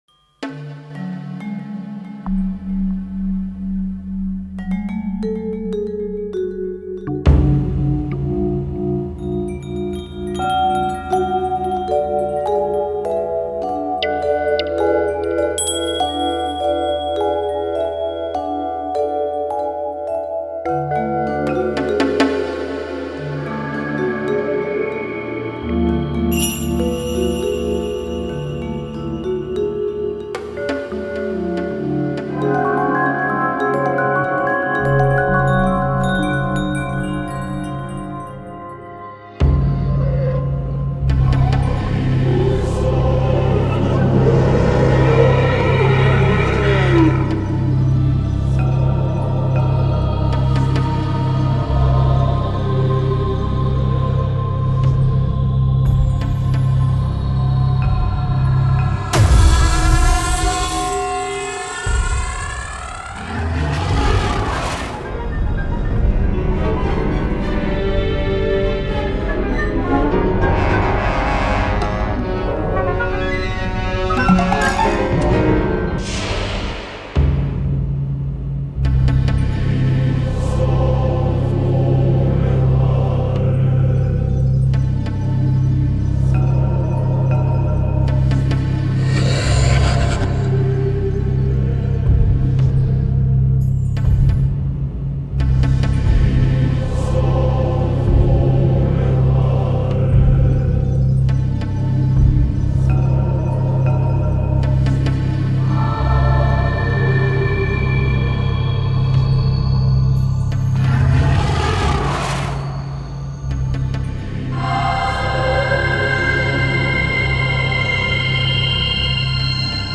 Cine de terror
terror
banda sonora
miedo
Sonidos: Música